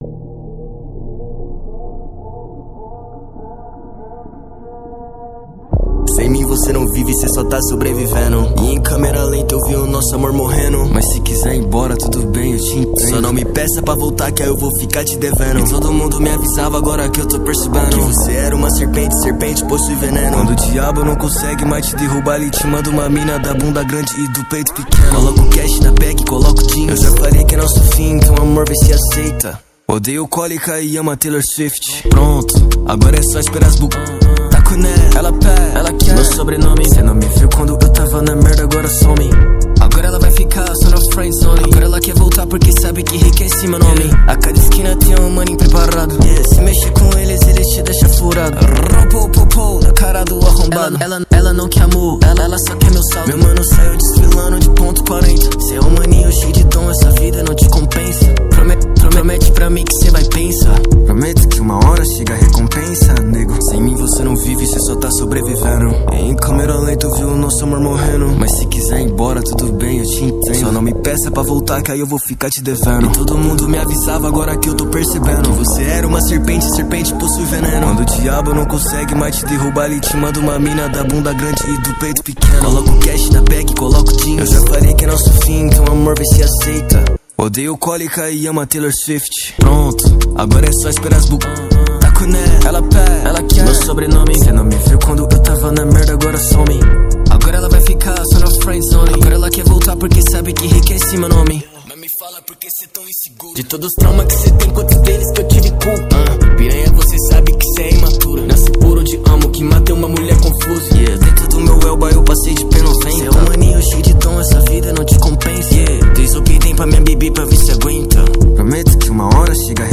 2024-09-25 22:26:42 Gênero: Trap Views